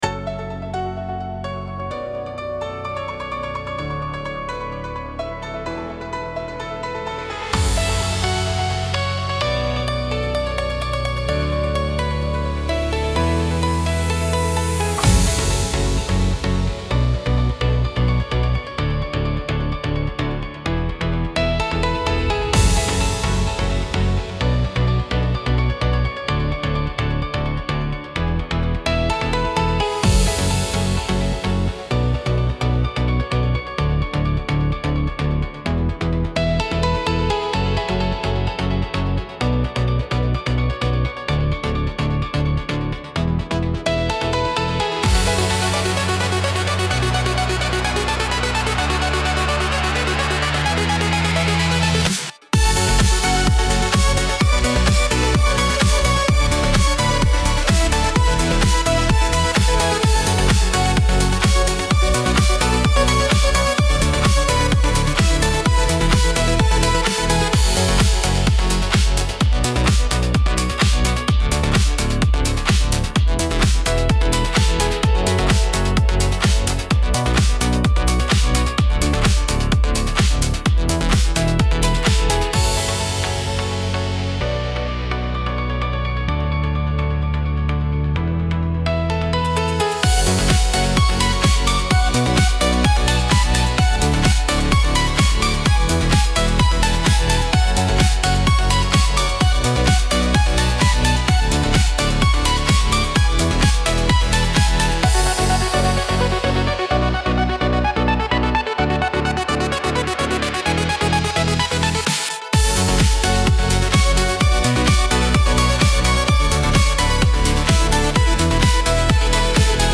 ДИНАМИЧНАЯ МУЗЫКА